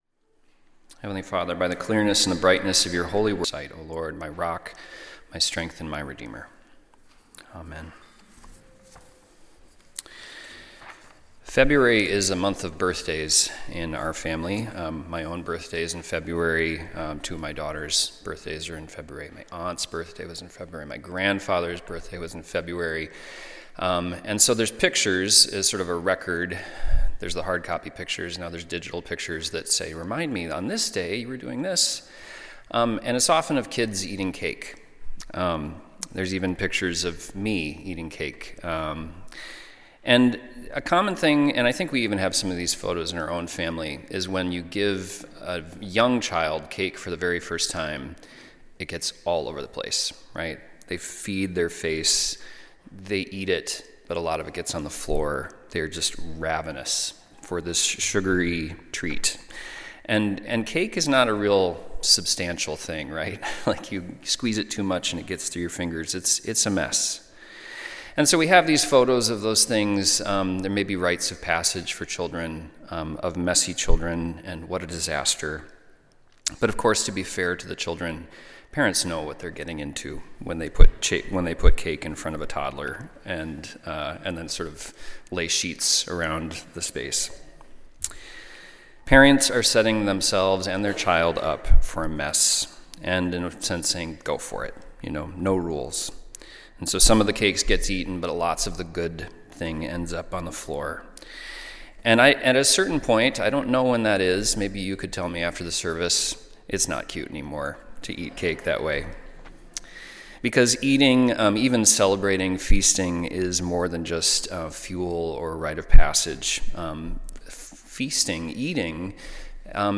Sunday Worship–February 22, 2026
Sermons